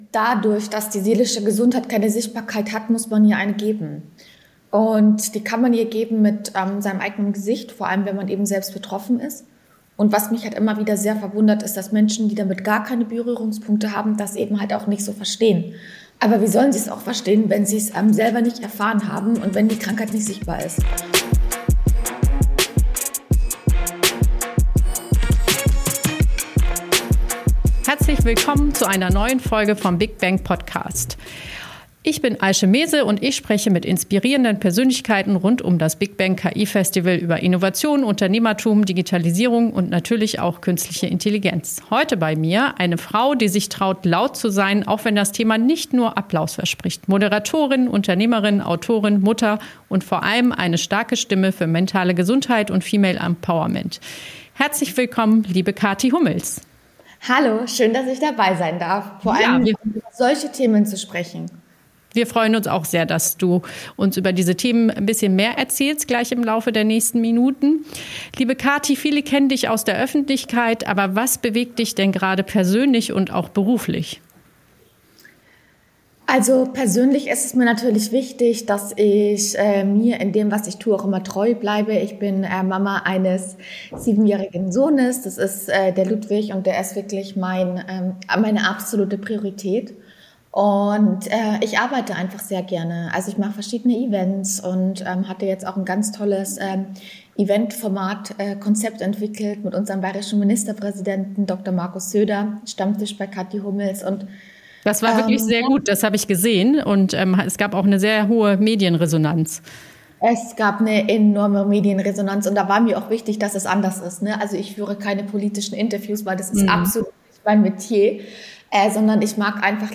Ein Talk über wahre Stärke